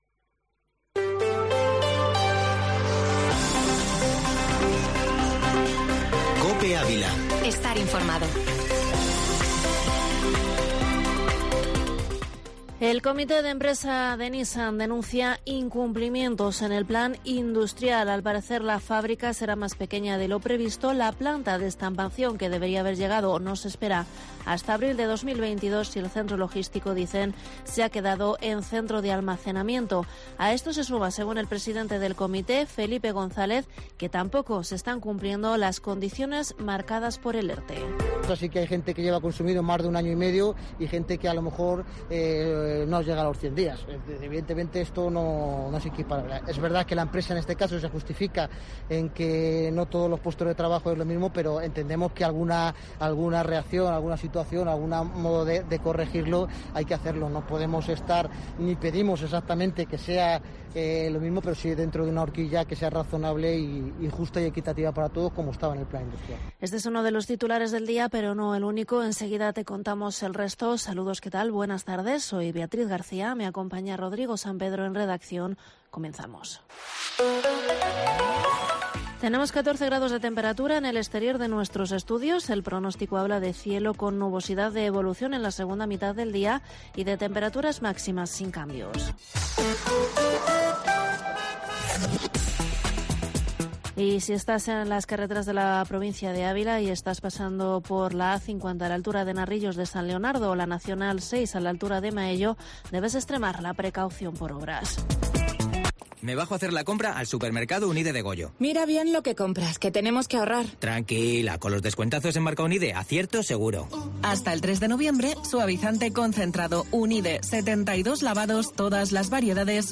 Informativo Mediodía COPE en Ávila 28/10/21